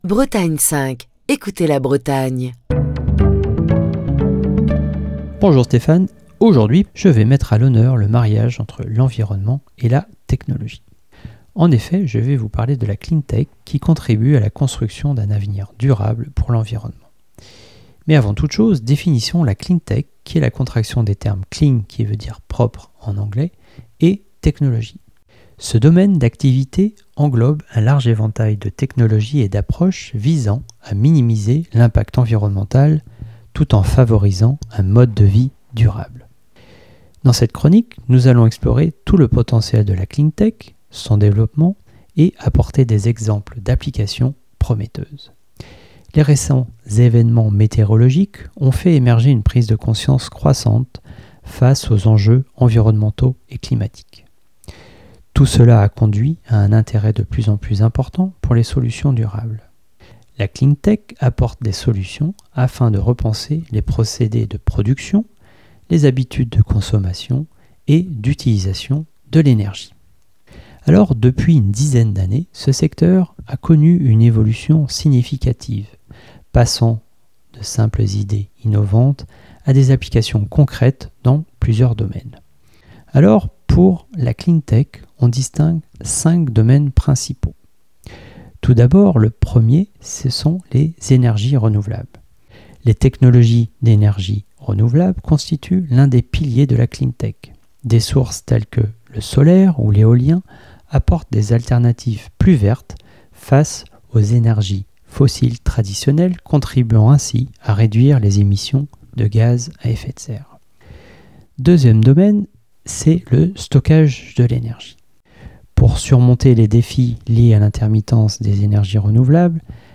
Chronique du 24 avril 2024. Beaucoup d'entreprises du secteur industriel s'inscrivent aujourd'hui dans une logique de transition énergétique, environnementale et climatique par le développement des technologies propres qui contribuent à la réduction de la pollution de l'air et de l'eau et participent aux économies d'énergie.